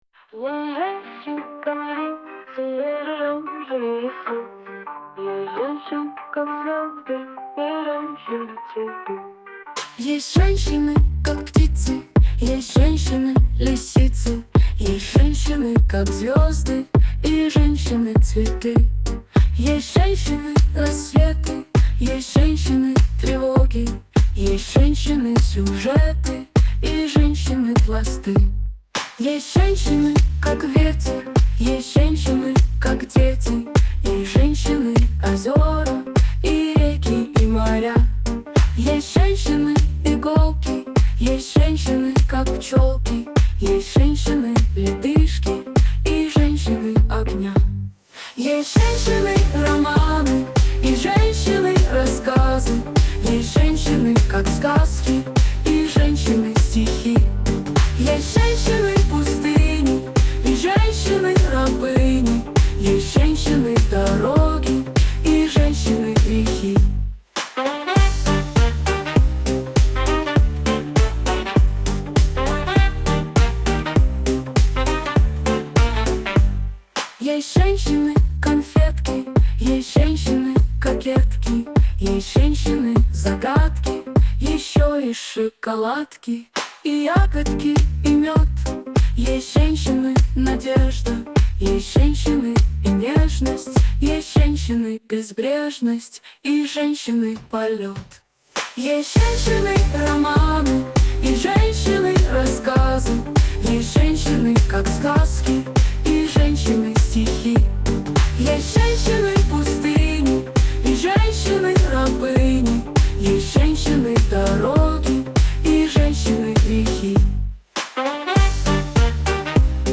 Ai Cover, 2024 - трек Был записан нейронкой.